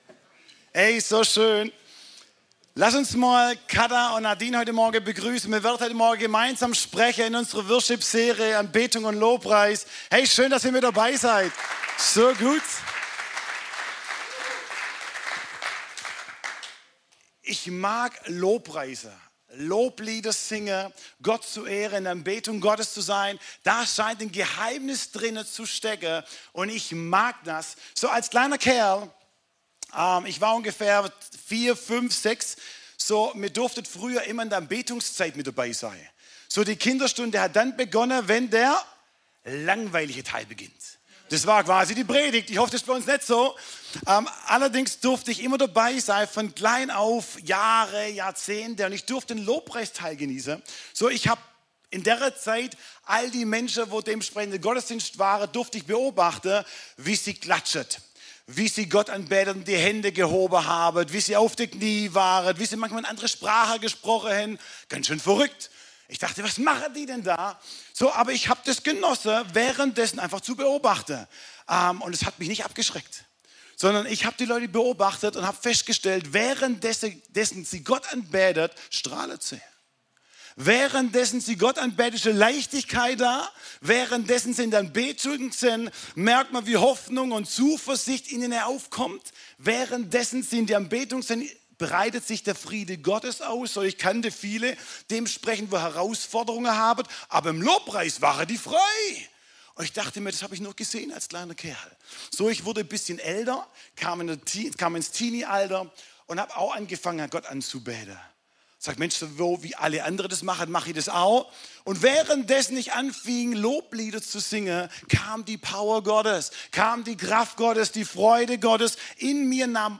26-31 Dienstart: Gottesdienst - Sonntagmorgen